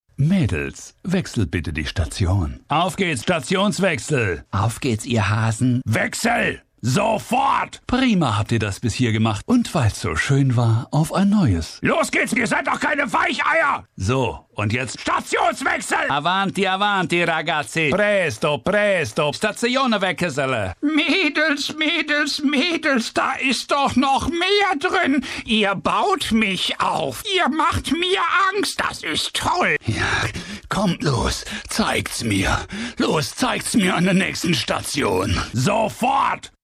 Hörspiel, Erzähler, Rollen, Charaktere